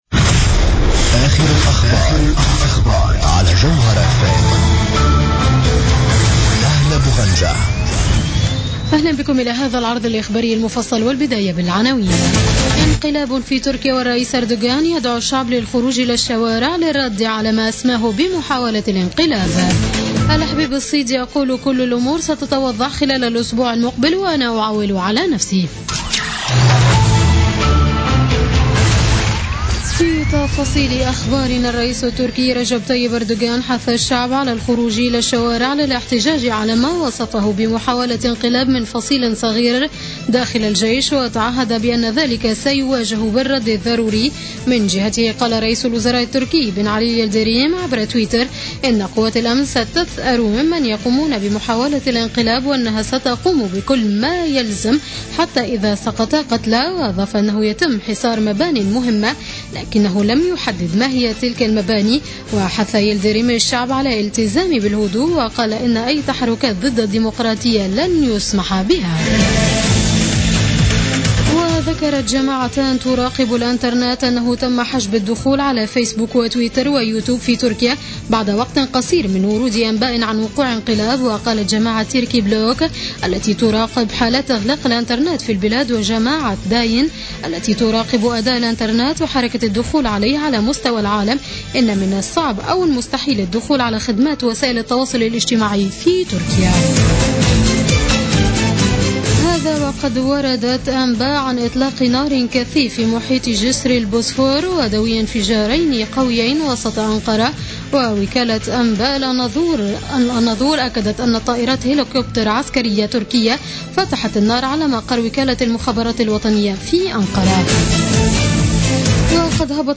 نشرة أخبار منتصف الليل ليوم السبت 16 جويلية 2016